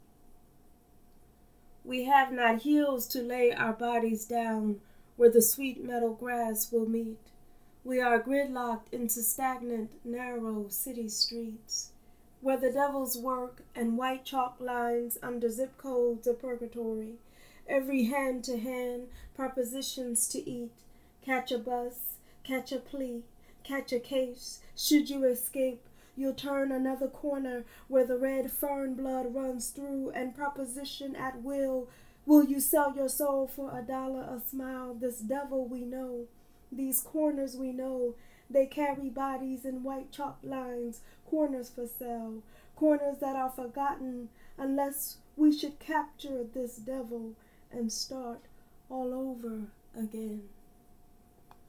Just exquisite and beautifully read. Do you want to know you have an error — you say “propositions” twice when it should be “proportions” the first time?